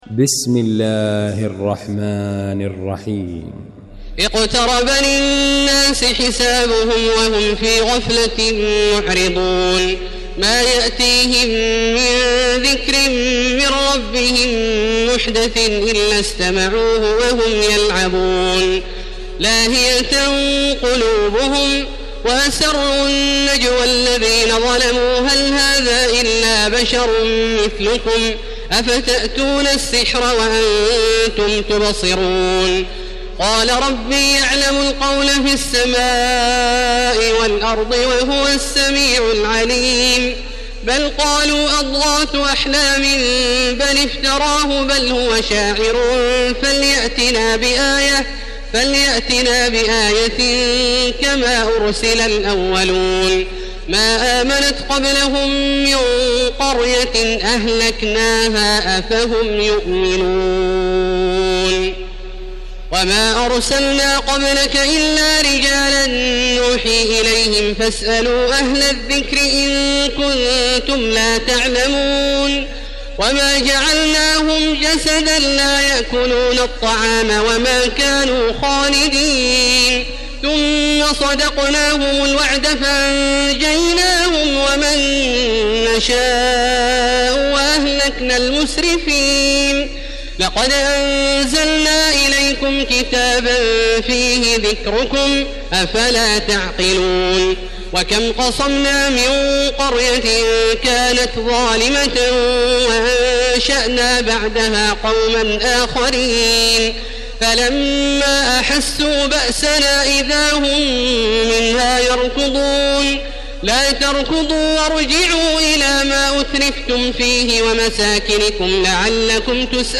المكان: المسجد الحرام الشيخ: فضيلة الشيخ عبدالله الجهني فضيلة الشيخ عبدالله الجهني الأنبياء